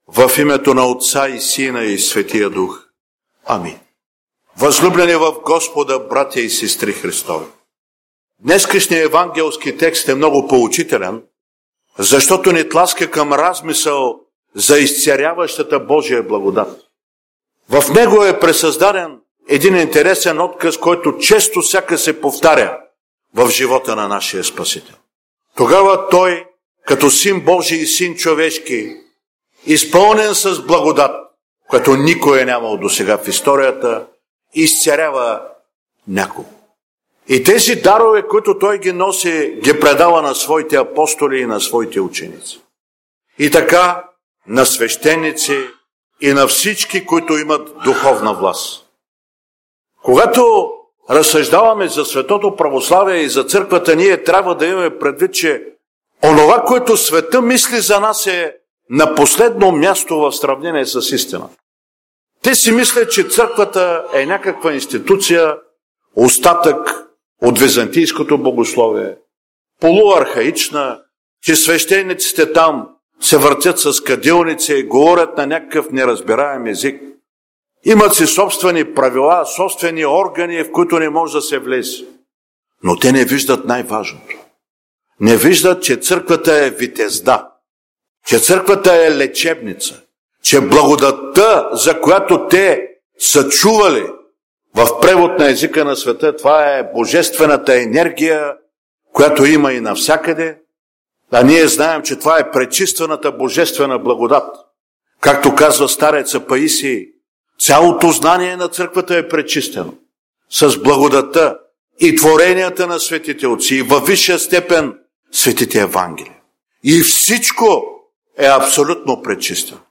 7 Неделя след Петдесетница – след Преображение – Проповед
Неделни проповеди